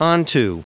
Prononciation du mot onto en anglais (fichier audio)